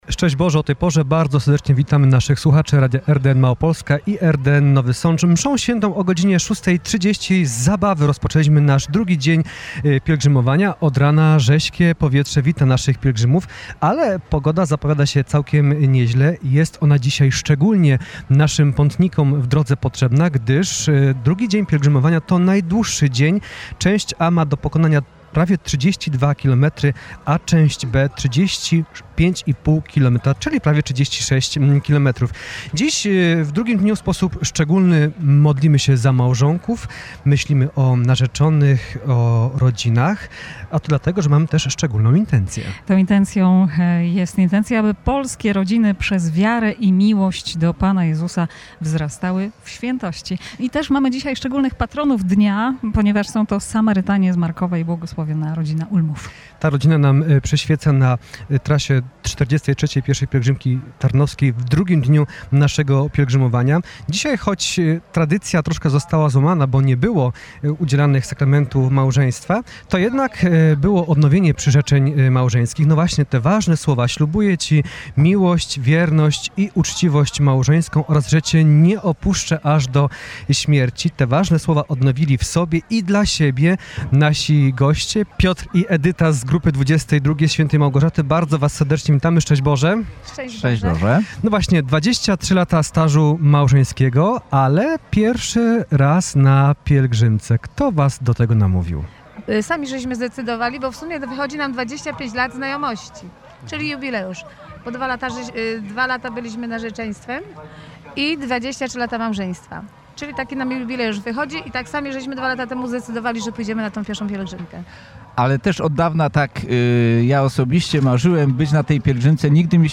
małżonkowie z 23-letnim stażem, którzy byli gośćmi studia pielgrzymkowego.
rozmowa-rano.mp3